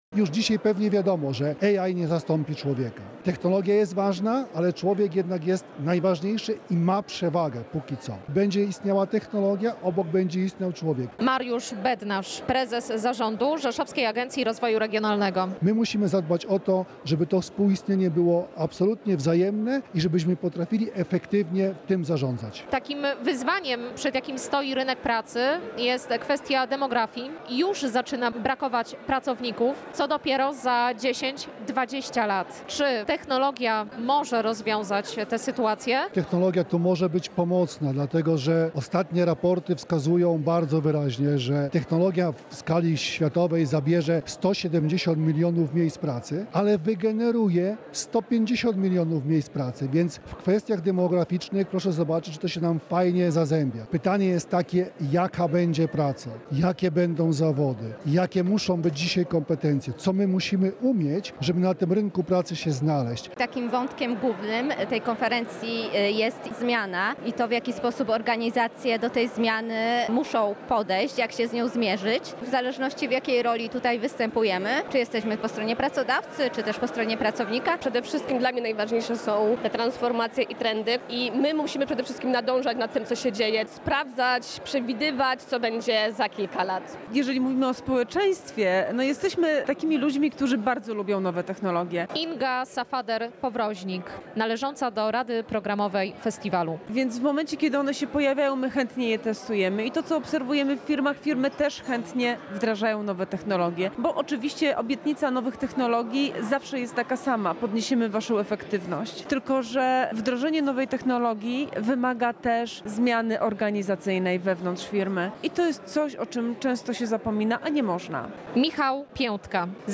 People Culture Tech Fest w Rzeszowie: technologia wspiera pracowników, ale ich nie zastąpi • Relacje reporterskie • Polskie Radio Rzeszów